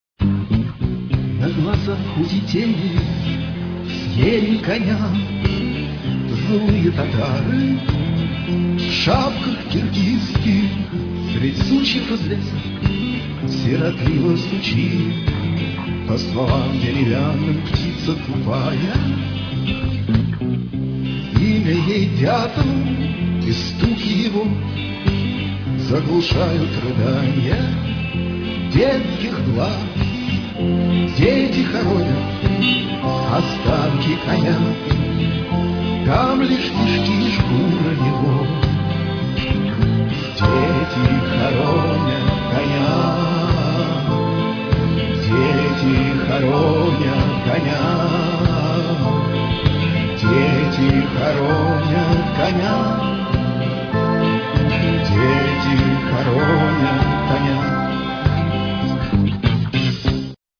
Концерт на Шаболовке(1996)
фрагмент песни ( 1 мин.)